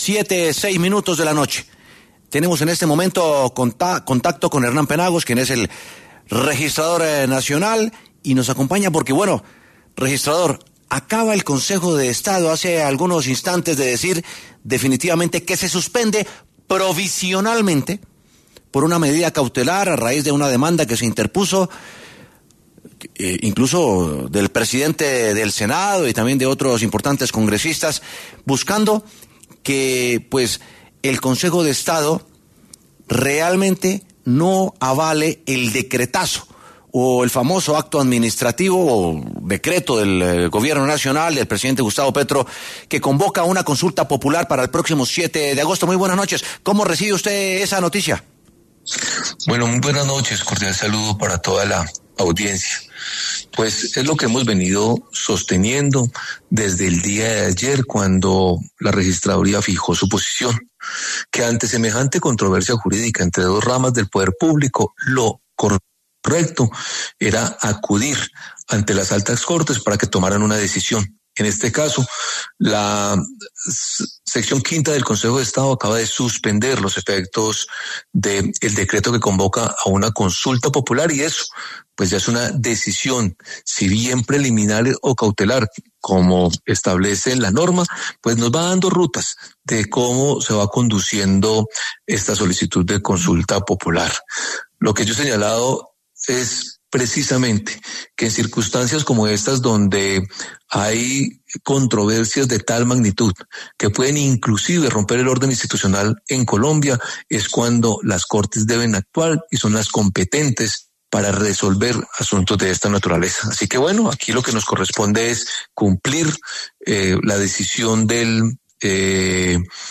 En esa línea, el registrador nacional, Hernán Penagos, pasó por los micrófonos de W Sin Carreta para referirse a la coyuntura.